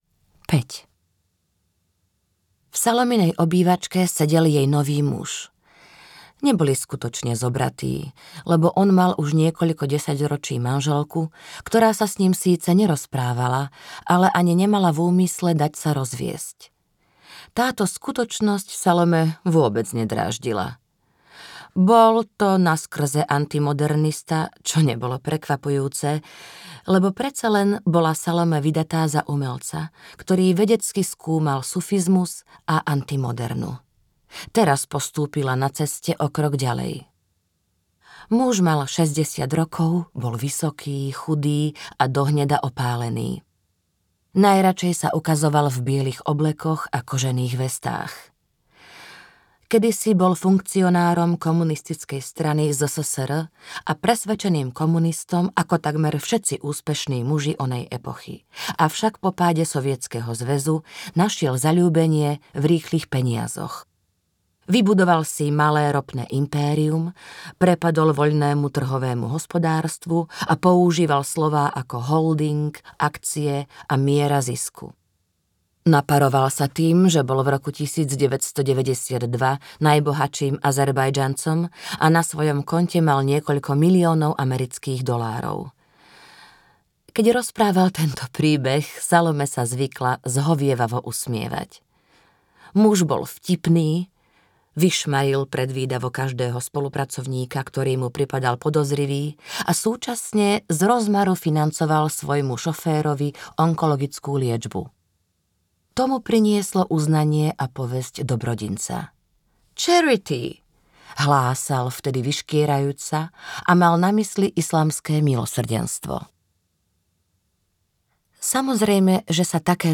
Právna nejasnosť jedného manželstva audiokniha
Ukázka z knihy